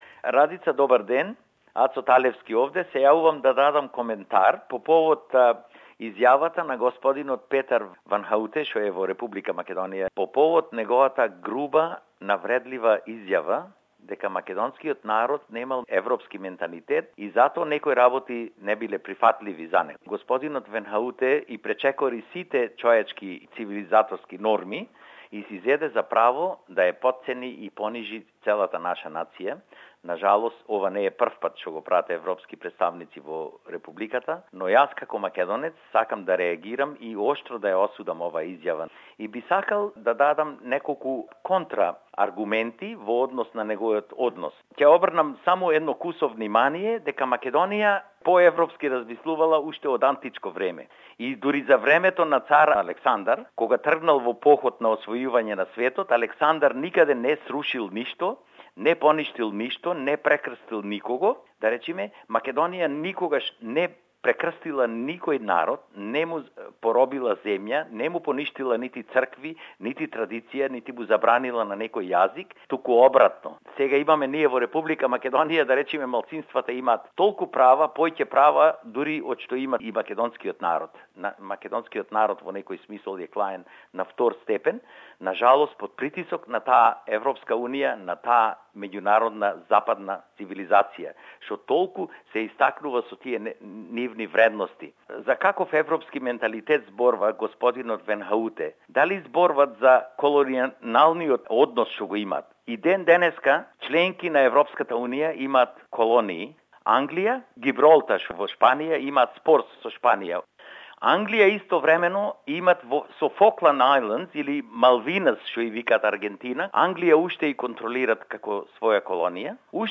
Voxpop